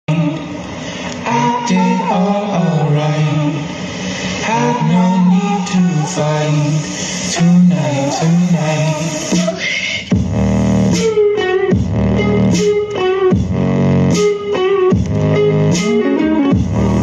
Excellent bass portable speaker, dual